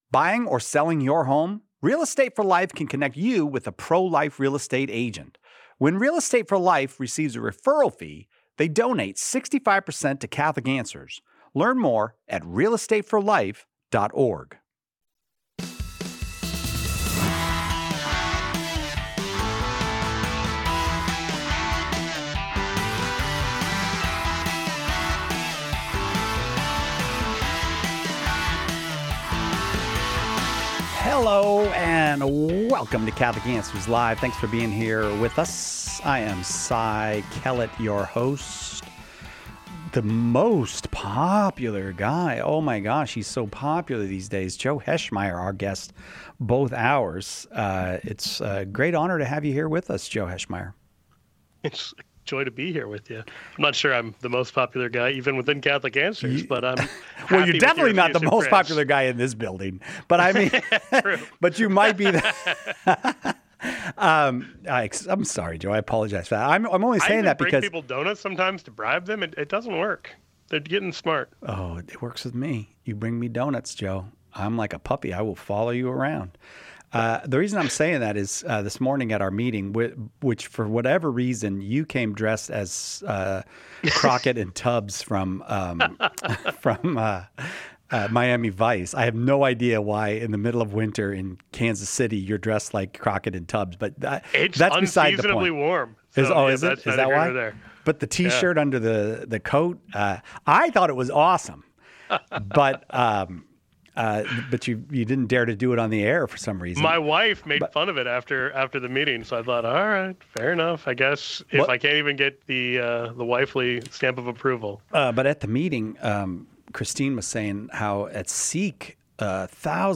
Examine the lasting nature of human fatherhood in eternity, the role of confession, and the Church's stance on IVF in this thought-provoking discussion.